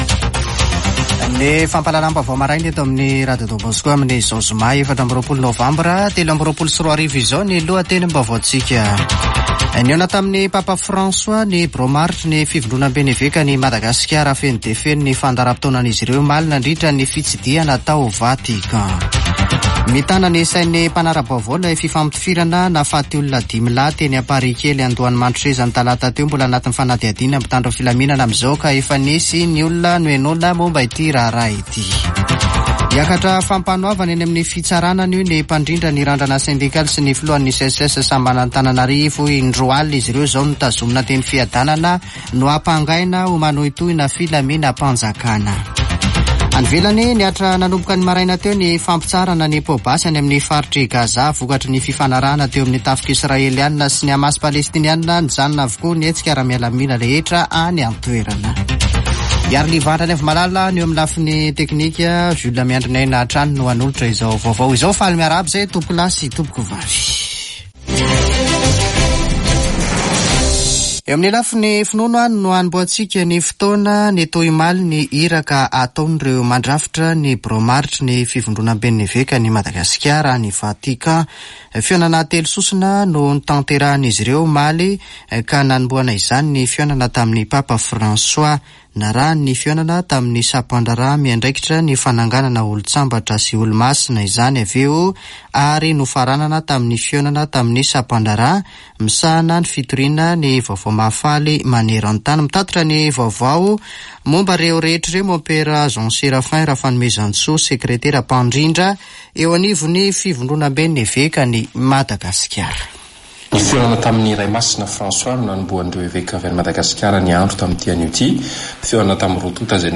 Radio Don Bosco - [Vaovao maraina] Zoma 24 nôvambra 2023